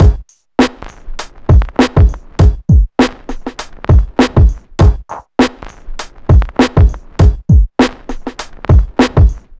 nu funky 100bpm 01.wav